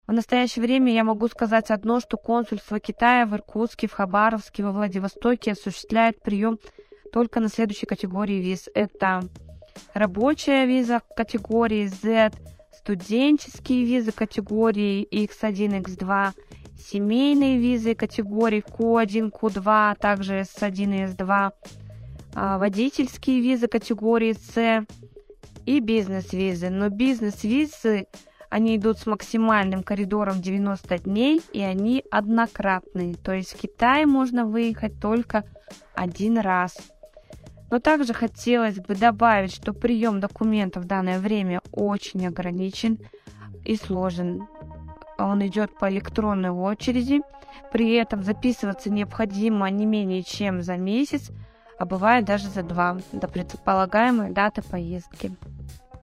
Комментарий